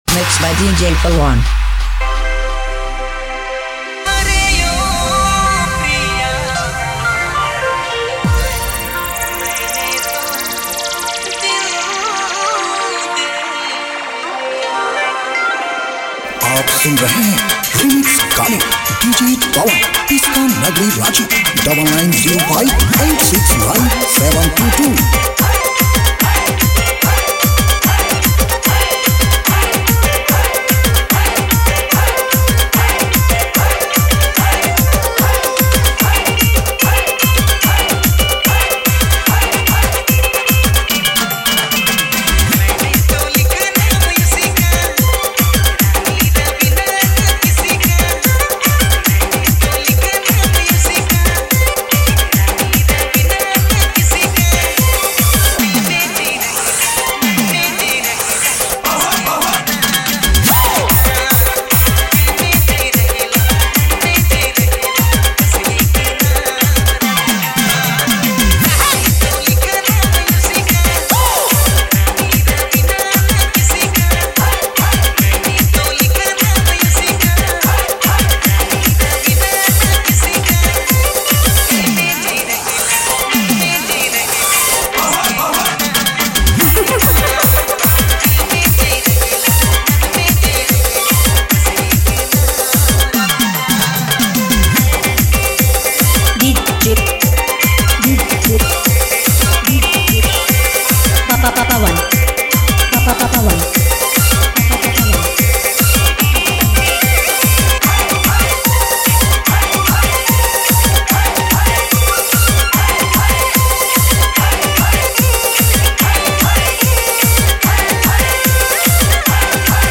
Download now and enjoy this high-energy remix!